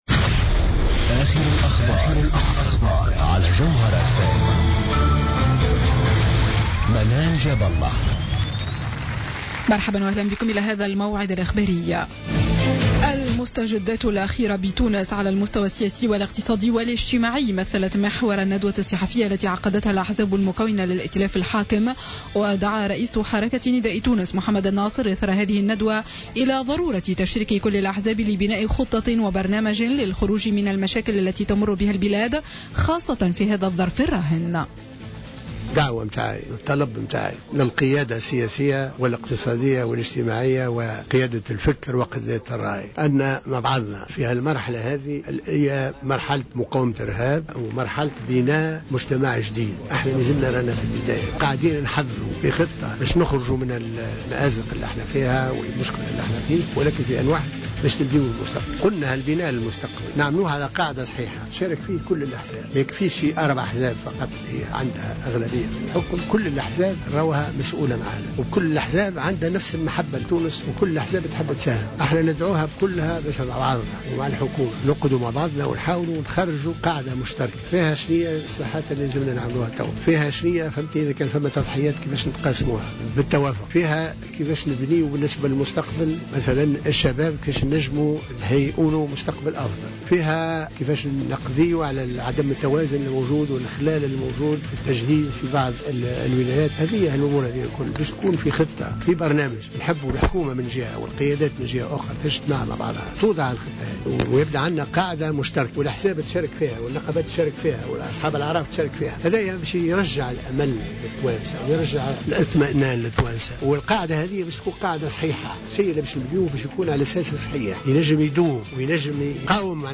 نشرة أخبار الخامسة مساء ليوم الاثنين 13 جويلية 2015